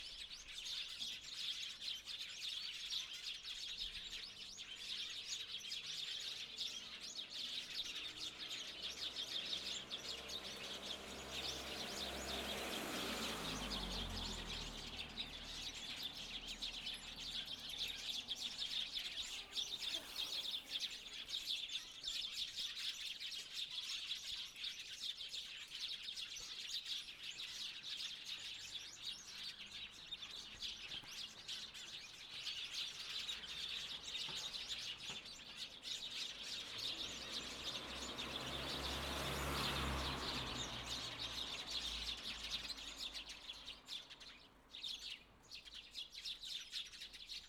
まずは鳥の鳴き声というか、街の雑踏という感じの音から。場所は住宅地の一角で生垣にたくさんのスズメがいつも鳴いている場所だ。
ここでは96kHzの設定でフロント・リアともにオンにし、設定は120°で手持ちで録音している。
フロントとリアでそこまで音の違いは感じないが、やはりスズメの鳴き声のクッキリさはフロント側なのが分かるはずだ。
H2essential_bird_front.wav